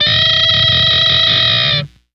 Index of /90_sSampleCDs/Zero-G - Total Drum Bass/Instruments - 2/track42 (Guitars)